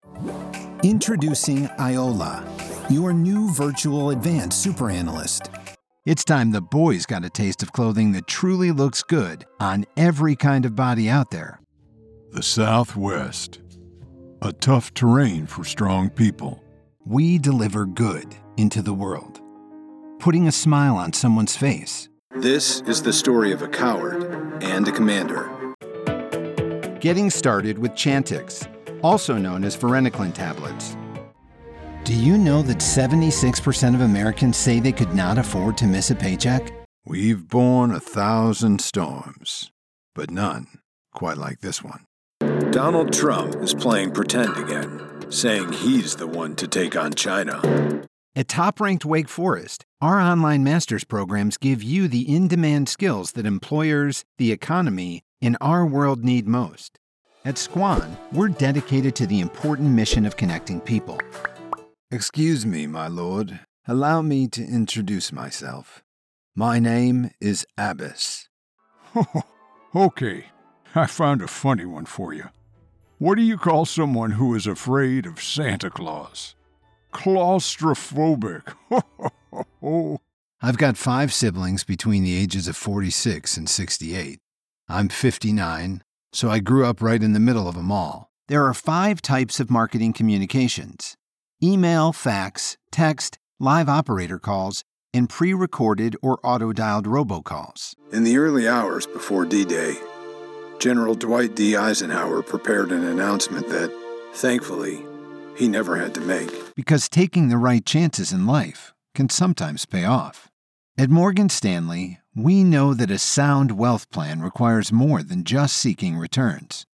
Demos
KWVoiceReel.mp3